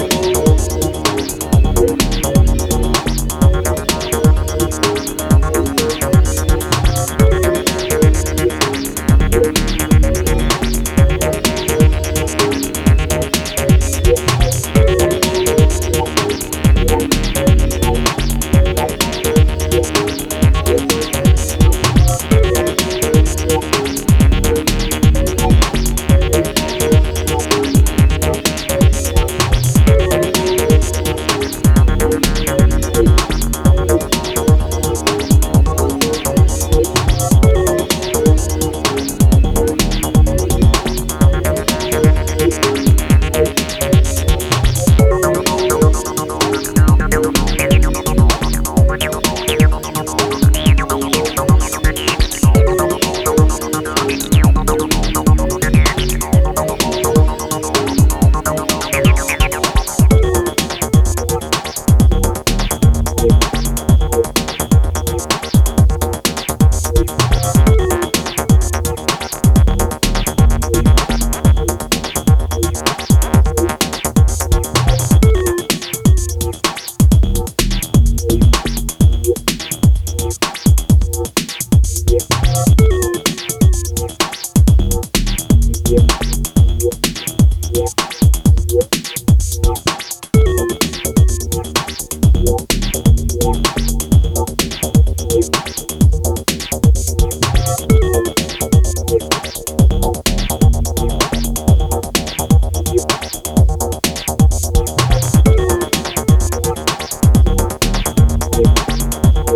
2x12inch Vinyl
ranges between ambient, breaks, acid techno and trance
ethereal opener
clears the mind of outside cares with ocean-deep dub